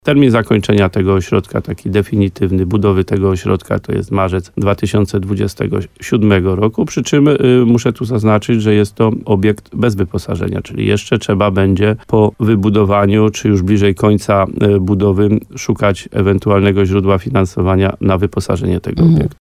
Prace będą trwały przez blisko trzy lata – mówi wójt gminy Łososina Dolna, Adam Wolak.